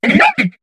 Cri de Miradar dans Pokémon HOME.